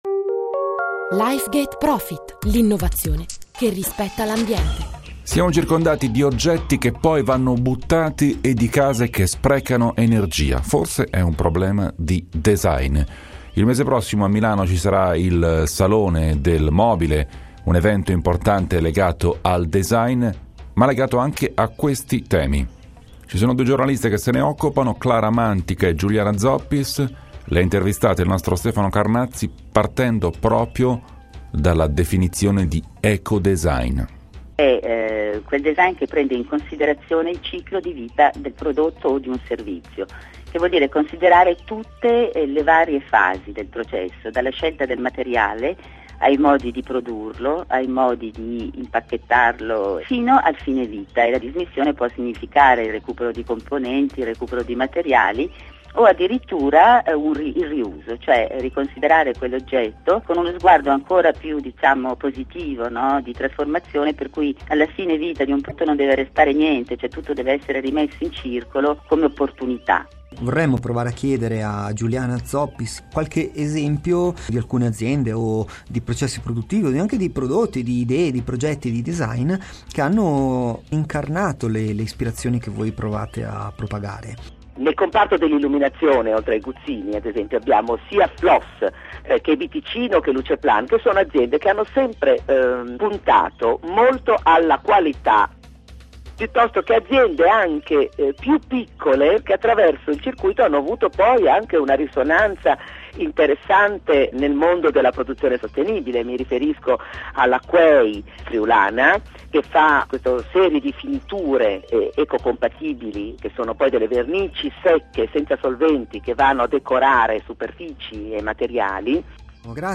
BEST UP ALLA RADIO 2009
Intervista a Lifegate marzo 2009